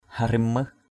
/ha-rɪm – mɯh/ (d.) tên một nhân vật lịch sử Chàm.